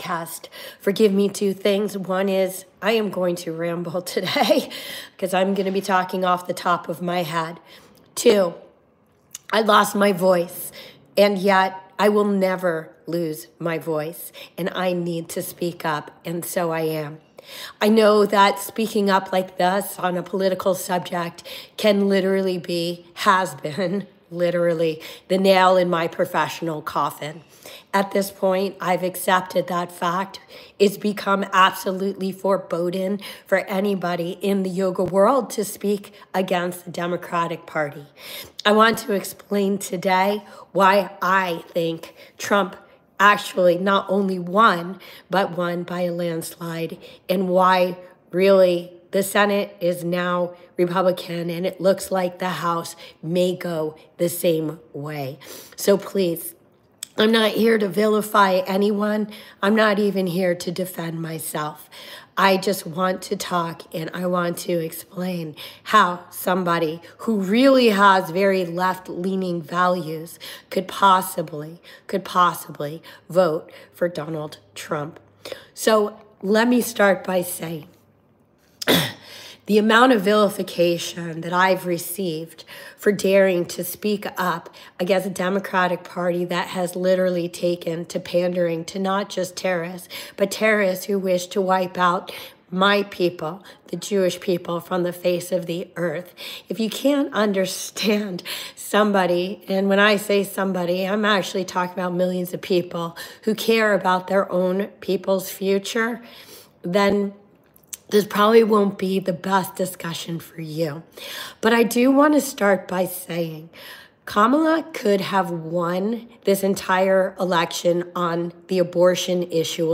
🙏 I've lost my actual voice...but will never stop speaking up! Trump won the 2024 US election.